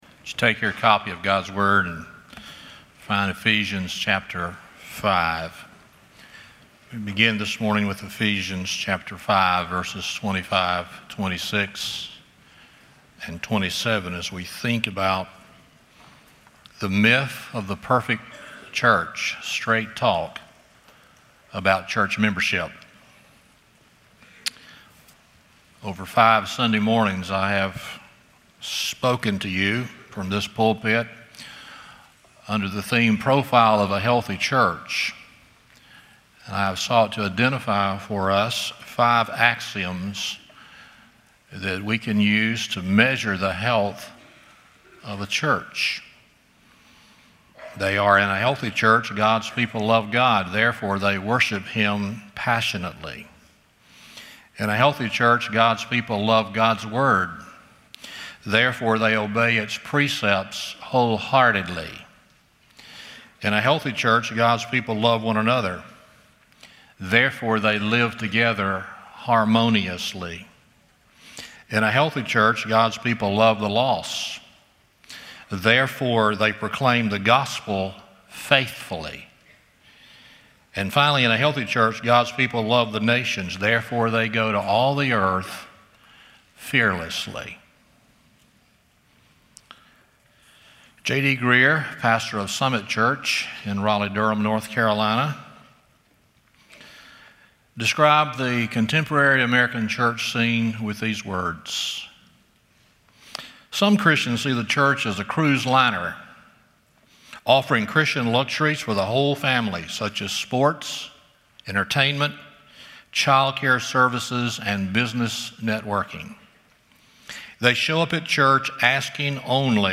Stand Alone Sermons
Service Type: Sunday Morning